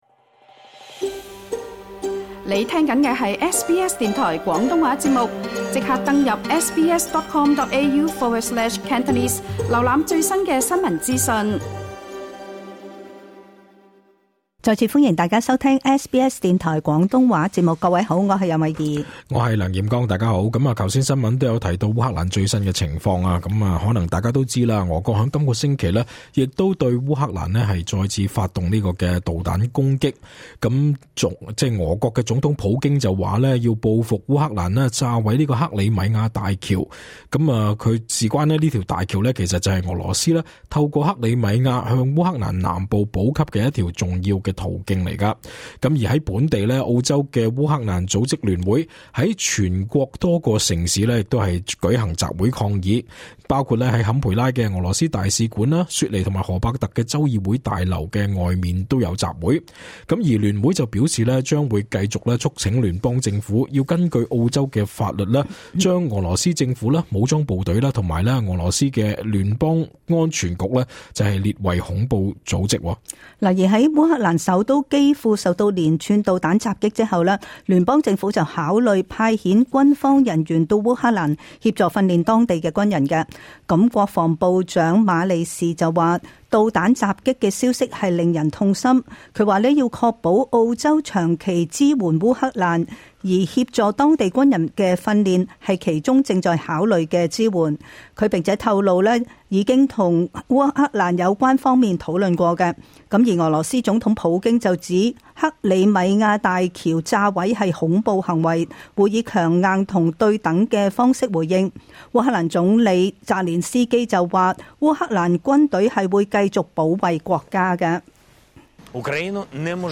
請聽今集【時事報道】。